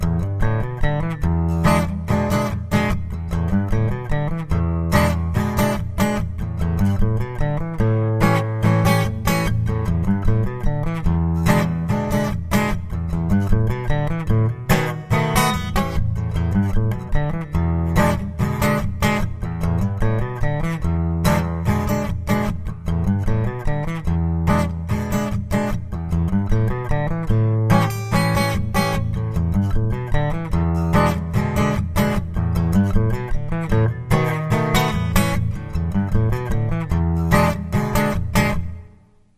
Die Mutation der Phrase hast Du aber nicht mitgekriegt - und gerade die gefällt mir im Original, weil sie viel bluesiger klingt als die reine Penta-Phrase. Dafür ist der Auftakt zur Phrase eine lecker Idee! Zum Schluß hast ein, zwei mal unsauber gegriffen ... wohl im Vorbeigehen recorded! :D